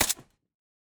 sounds / weapons / _bolt / ar15_3.ogg
ar15_3.ogg